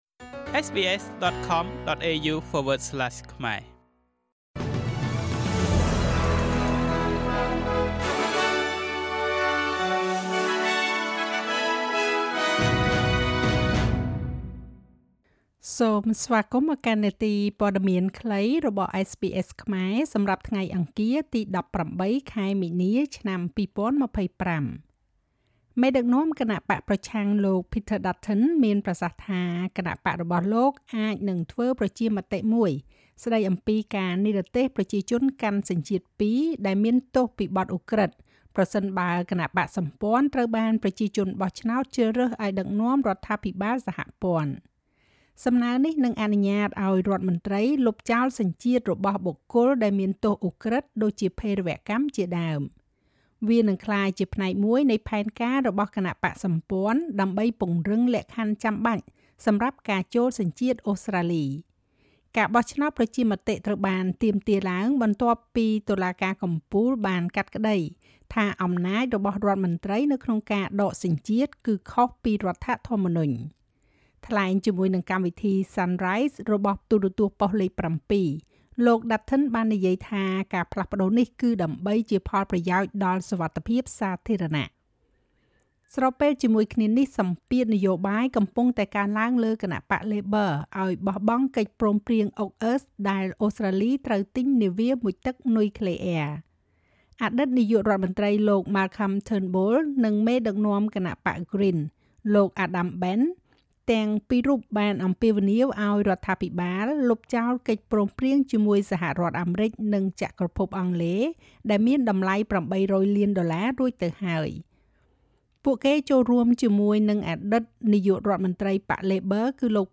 នាទីព័ត៌មានខ្លីរបស់SBSខ្មែរ សម្រាប់ថ្ងៃអង្គារ ទី១៨ ខែមីនា ឆ្នាំ២០២៥